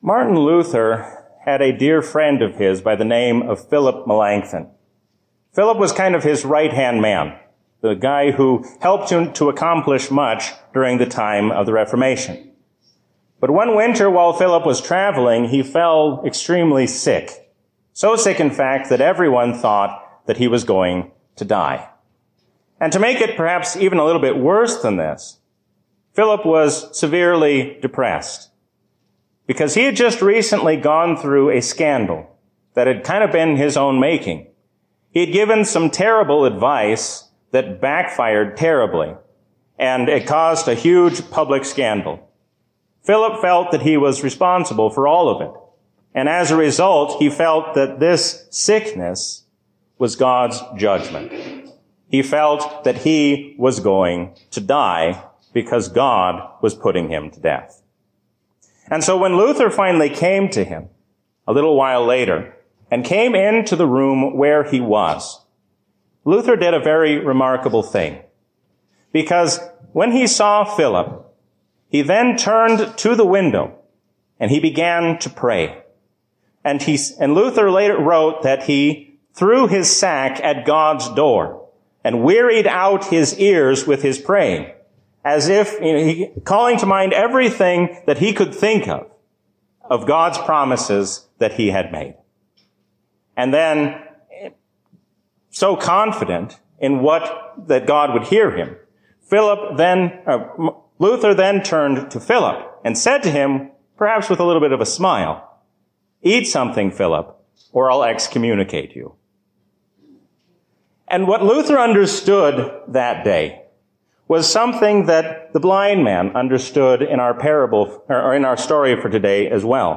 A sermon from the season "Trinity 2024." There is no reason to worry about Tuesday or any day to come when we remember that the Lord reigns as King forever.